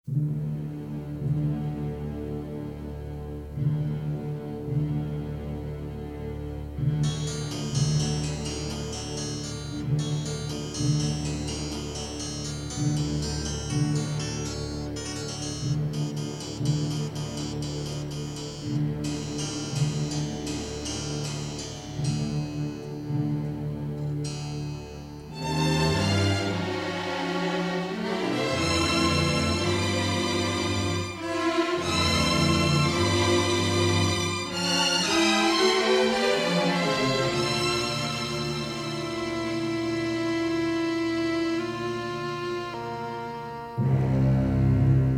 with notable use of harpsichord for sinister effect.
a soaring love theme